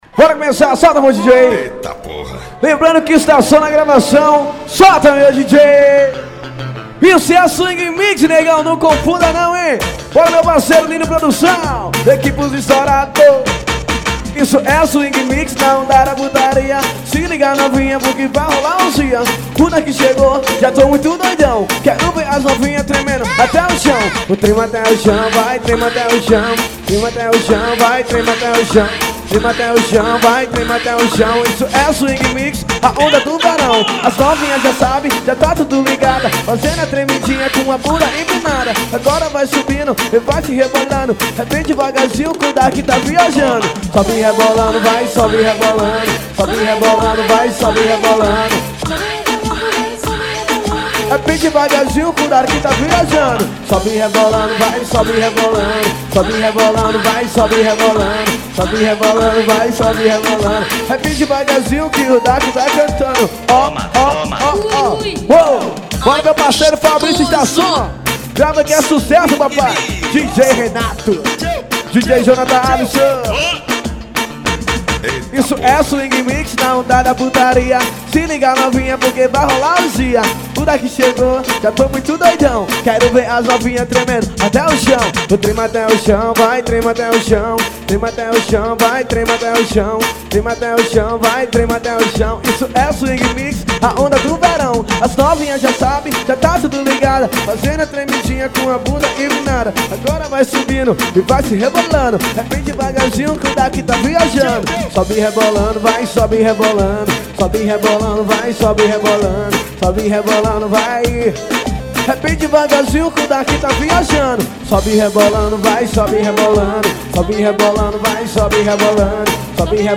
AO VIVO..